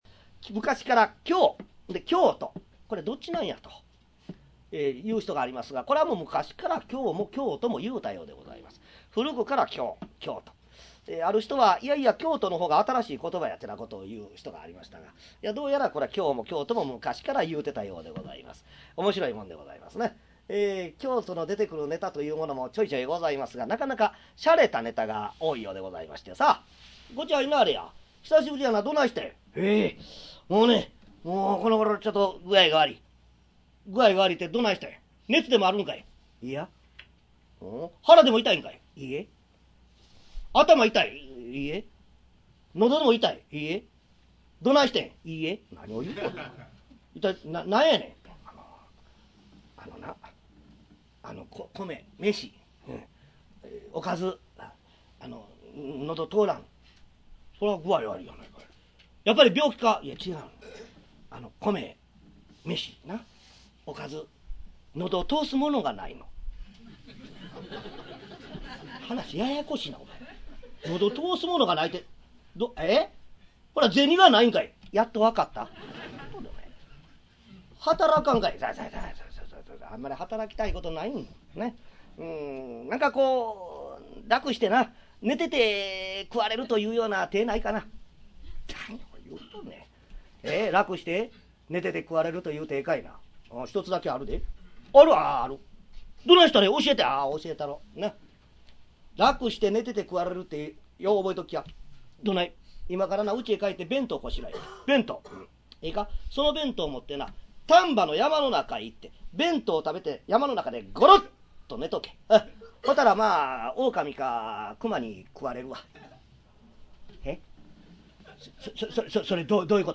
「猫間川寄席」の雰囲気を、そのままで！
約15年間、大阪玉造さんくすホールで、毎月開催されている「猫間川寄席」での、四代目桂文我の口演を収録した落語集。 書籍版「桂文我 上方落語全集」に掲載したネタを、ライブ公演の録音で楽しみ、文字の落語と、実際の口演との違いを再確認していただければ幸いです。